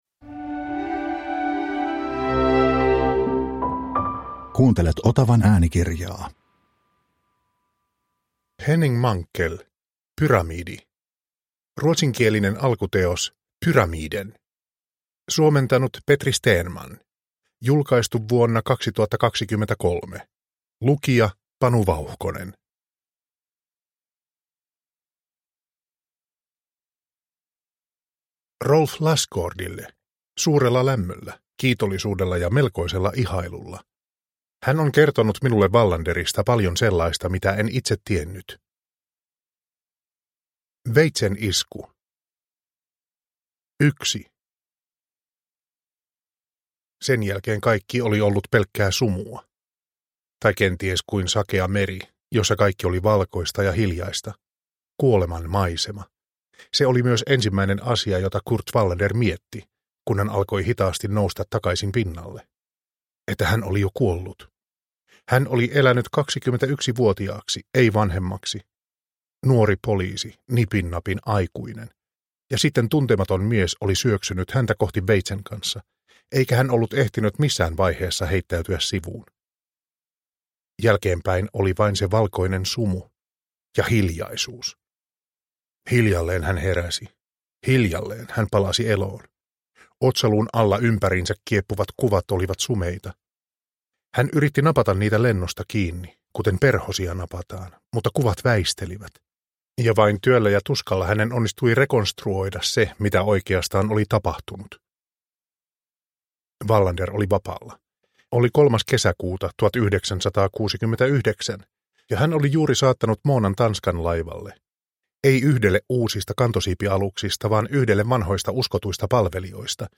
Pyramidi – Ljudbok – Laddas ner